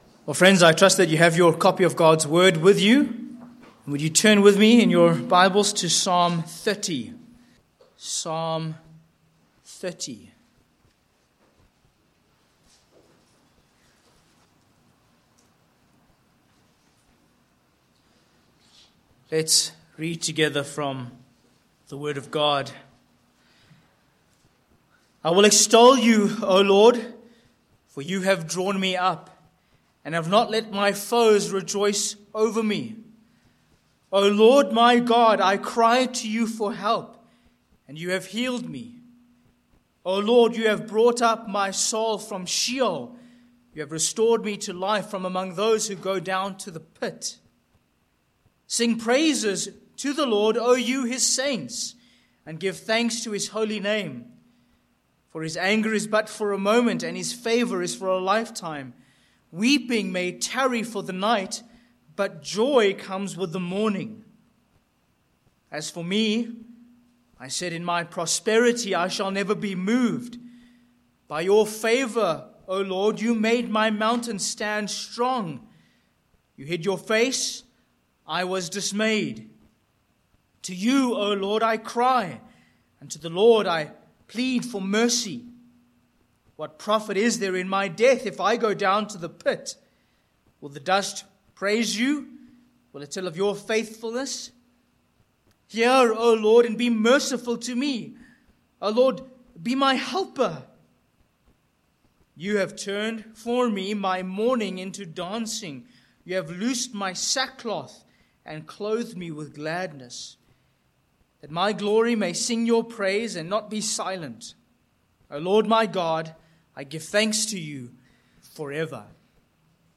Sermon Points: 1. Delivered from Death v1-3, 8-10